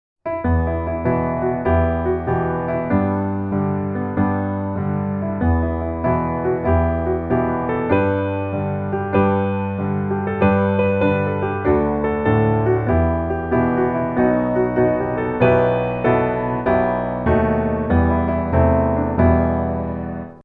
E Mineur